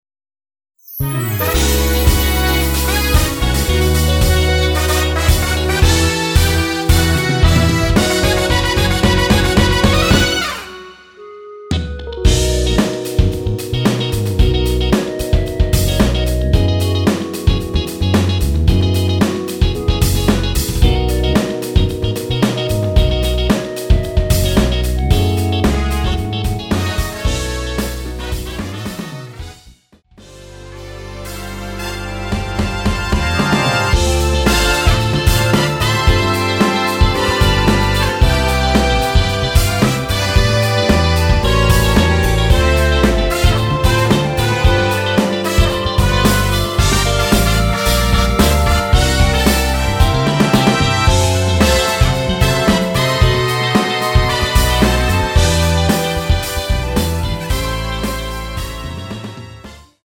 원키에서(+3)올린 멜로디 포함된 MR입니다.
멜로디 MR이란
앞부분30초, 뒷부분30초씩 편집해서 올려 드리고 있습니다.
중간에 음이 끈어지고 다시 나오는 이유는